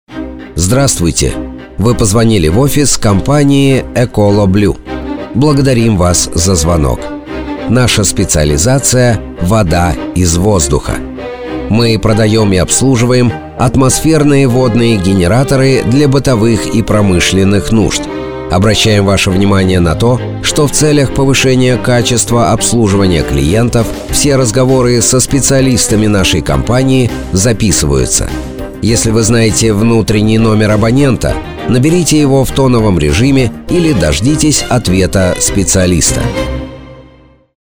Ecoloblue – Голосовое приветствие IVR
Ecoloblue-Голосовое-приветствие-IVR-.mp3